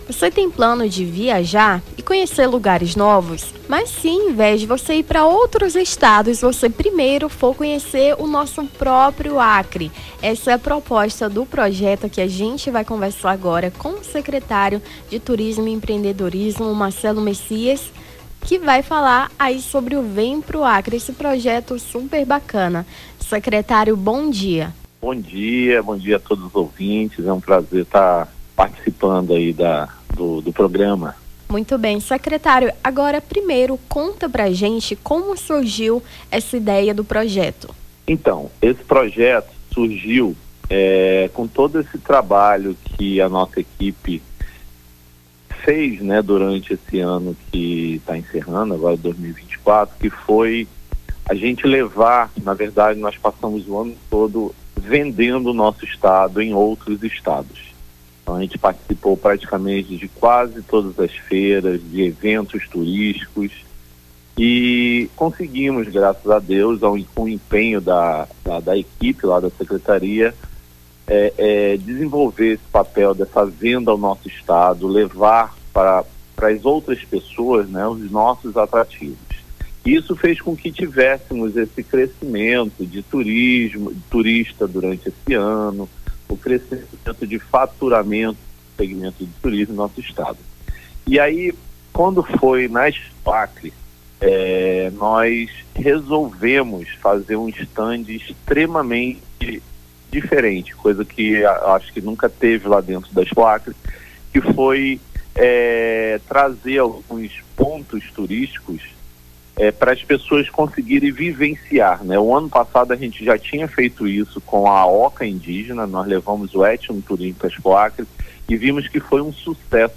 conversou com Marcelo Messias, secretário de estado de turismo e empreendedorismo (sete)
Nome do Artista - CENSURA - ENTREVISTA TURISMO ACRE (20-12-24).mp3